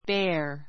béə r ベ ア